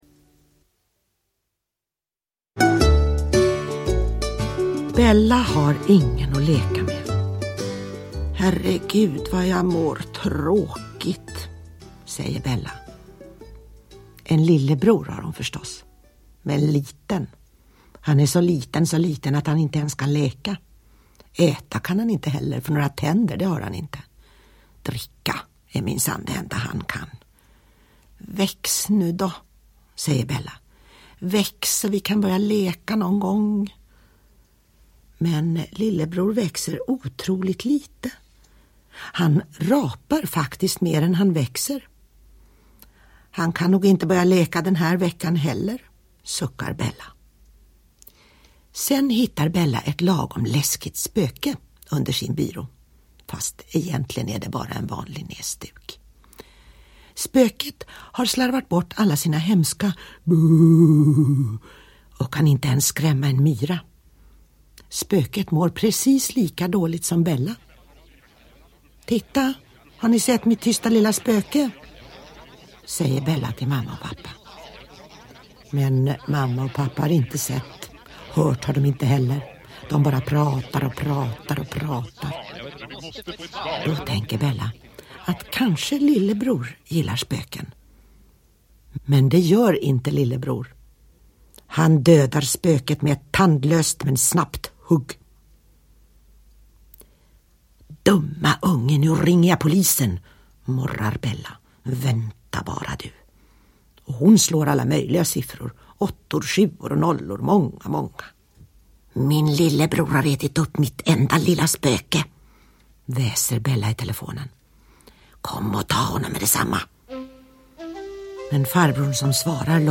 Boken om Bella och Gustav – Ljudbok – Laddas ner
Här i en fantastisk uppläsning av Claire Wickholm.
Uppläsare: Claire Wikholm